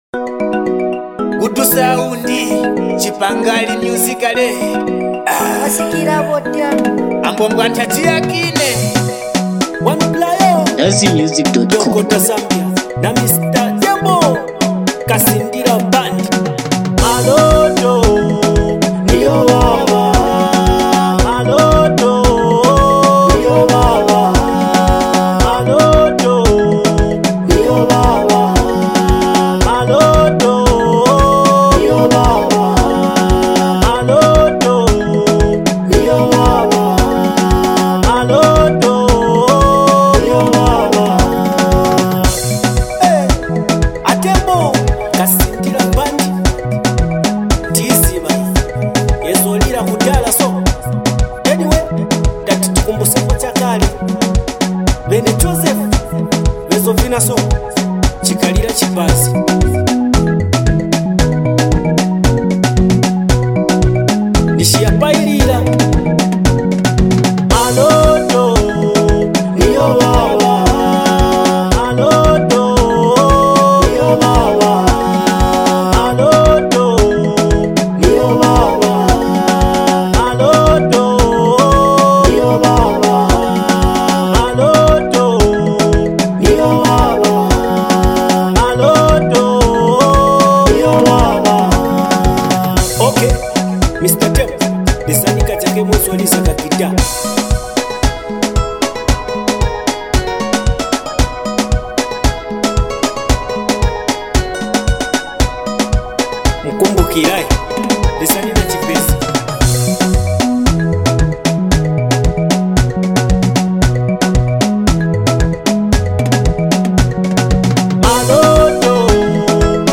which good and Dancehall